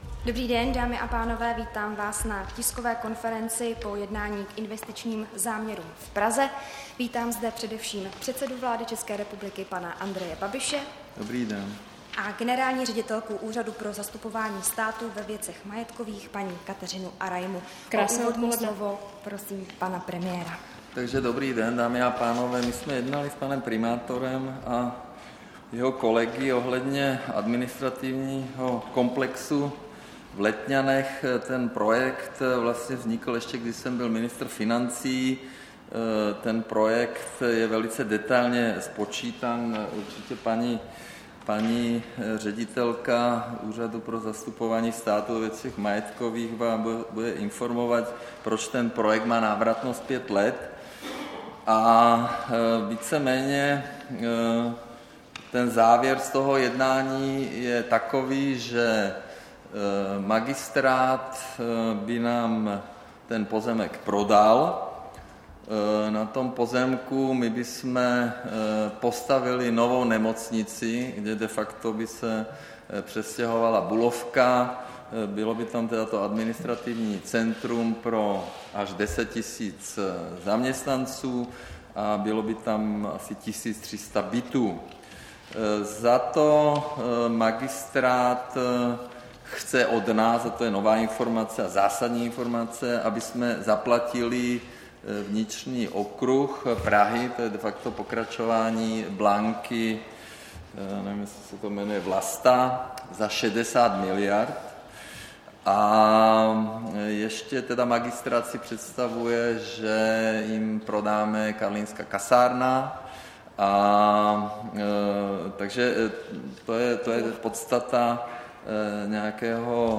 Tisková konference po jednání k investičním záměrům v Praze, 30. ledna 2019